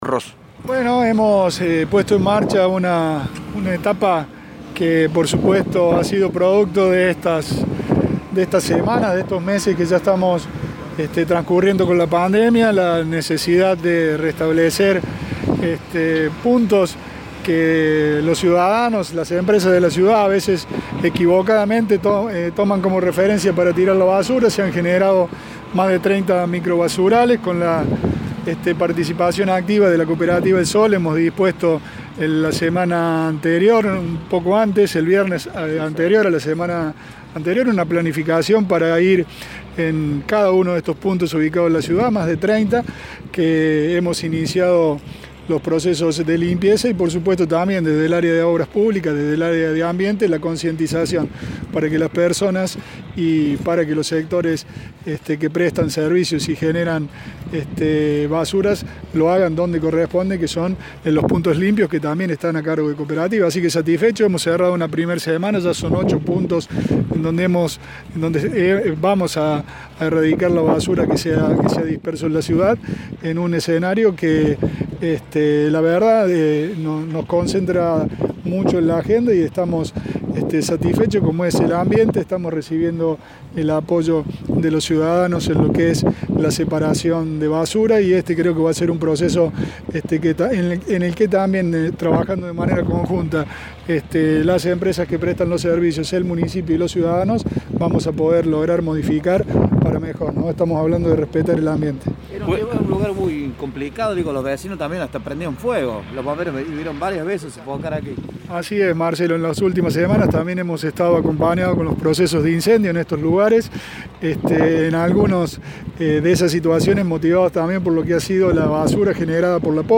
El intendente interino Pablo Rosso habló con los medios en el lugar de los trabajos.